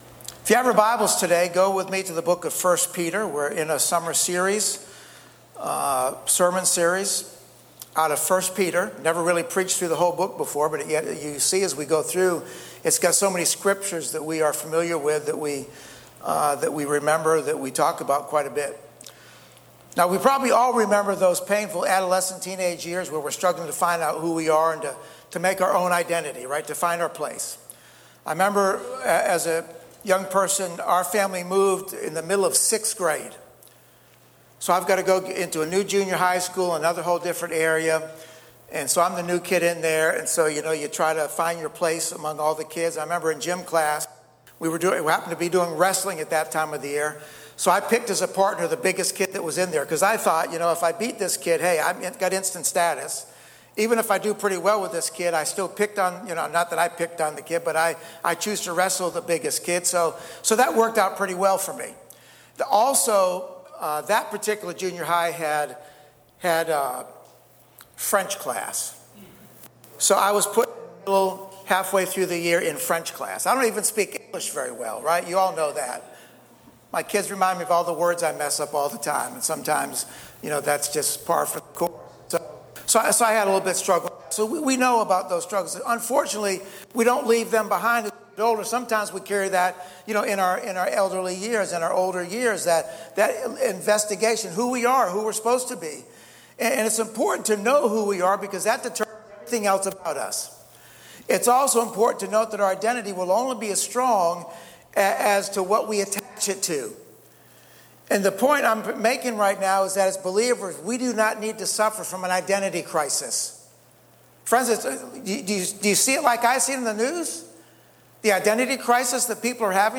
Online-Church-Aug-3-AUDIO.mp3